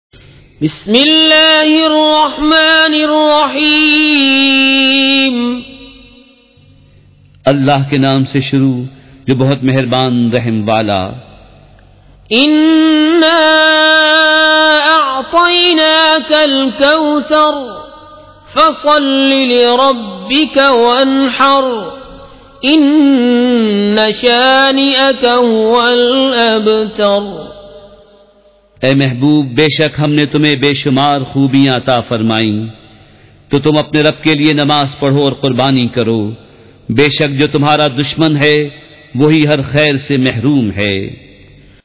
سورۃ الکوثر مع ترجمہ کنزالایمان ZiaeTaiba Audio میڈیا کی معلومات نام سورۃ الکوثر مع ترجمہ کنزالایمان موضوع تلاوت آواز دیگر زبان عربی کل نتائج 5557 قسم آڈیو ڈاؤن لوڈ MP 3 ڈاؤن لوڈ MP 4 متعلقہ تجویزوآراء